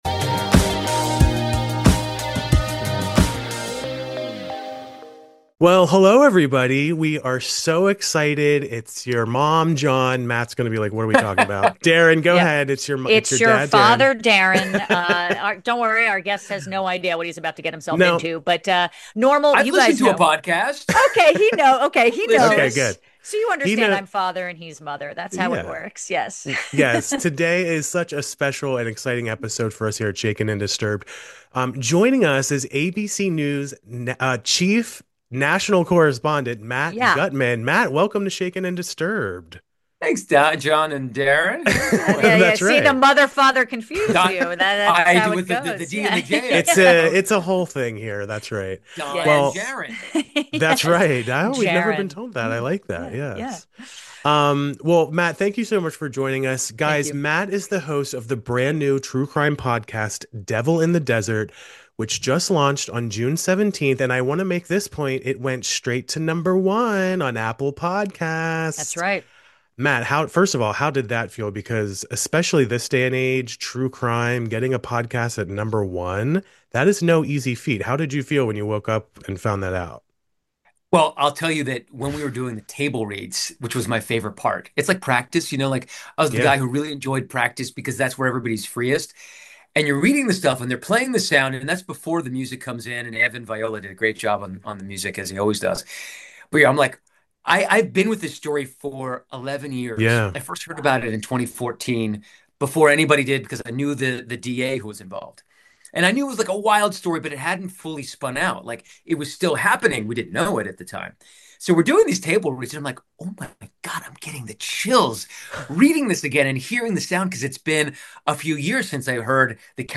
We got to sit down with ABC News chief national correspondent Matt Gutman, who is the host of the ABC News true crime podcast "Devil In The Desert."